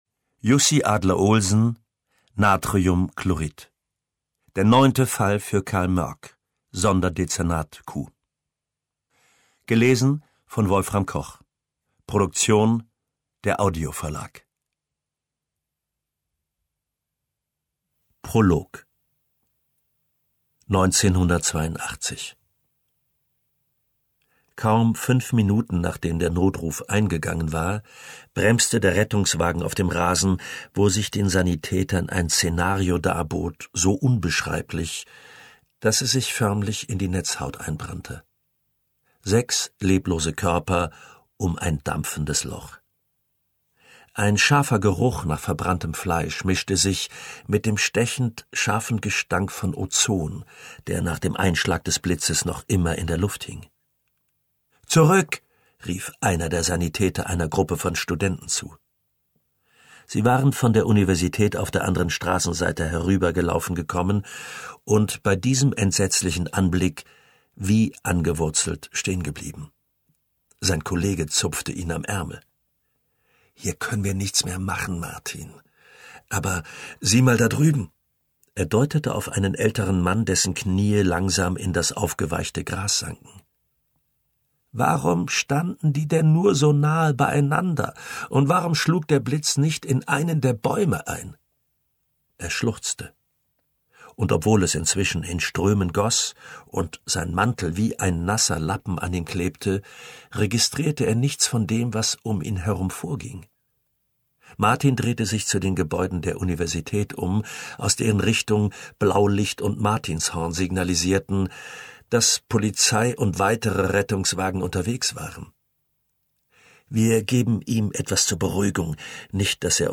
Wolfram Koch (Sprecher)
Ungekürzte Lesung